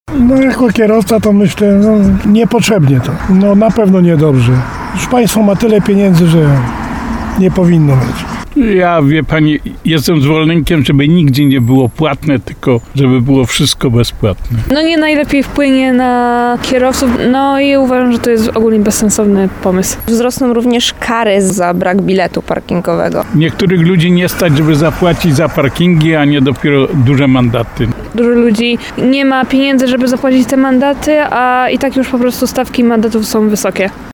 Zapytani przez nas mieszkańcy miasta krytykują decyzję radnych.